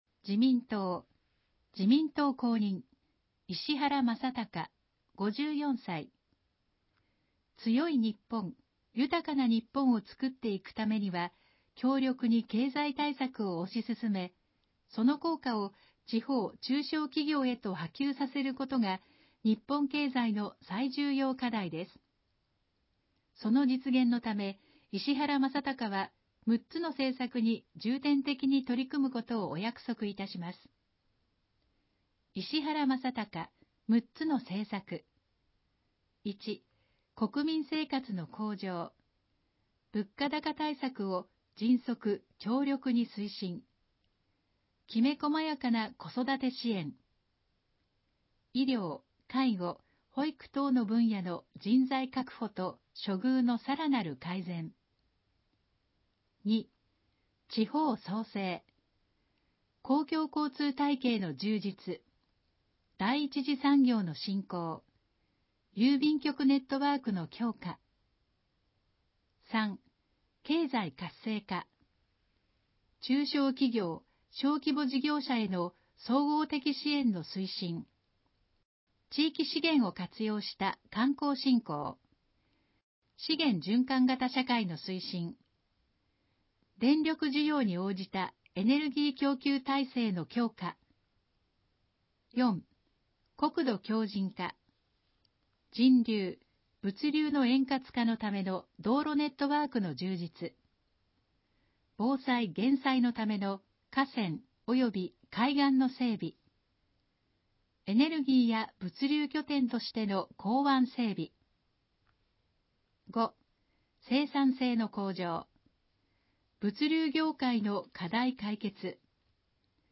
選挙のお知らせ版（音声版）（三重県視覚障害者協会）